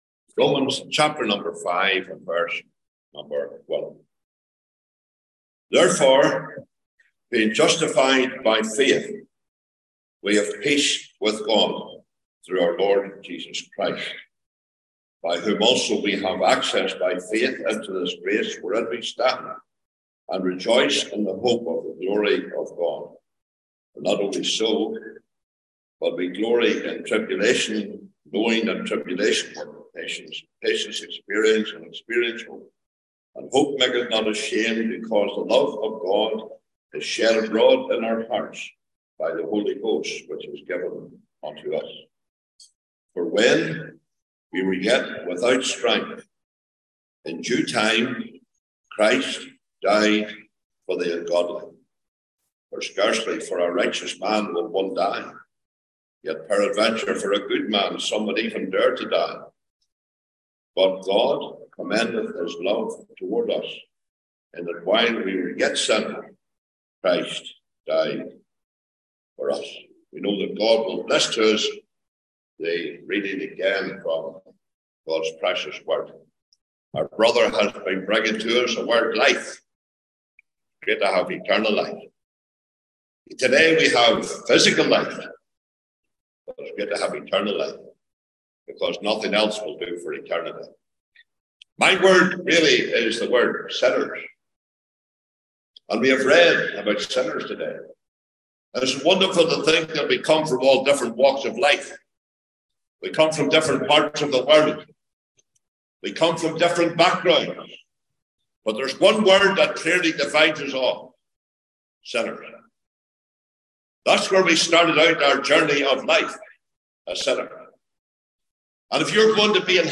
Gospel
(Cooroy Conference 2023)